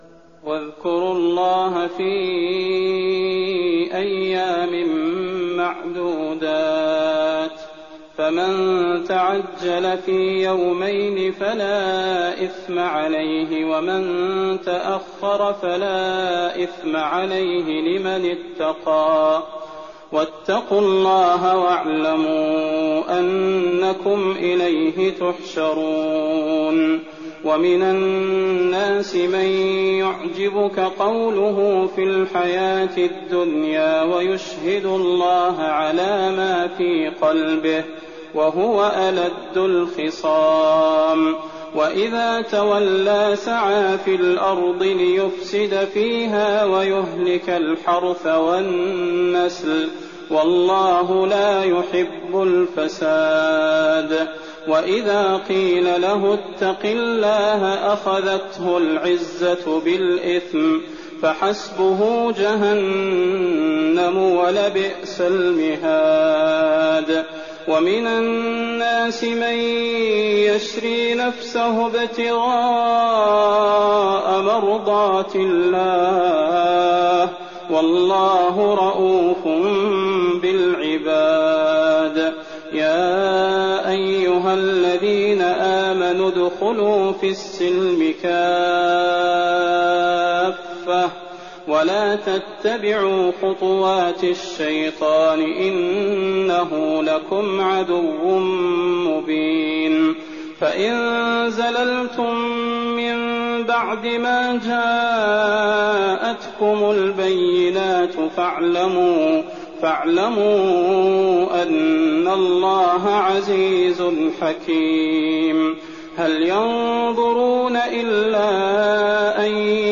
تراويح الليلة الثانية رمضان 1419هـ من سورة البقرة (203-252) Taraweeh 2nd night Ramadan 1419H from Surah Al-Baqara > تراويح الحرم النبوي عام 1419 🕌 > التراويح - تلاوات الحرمين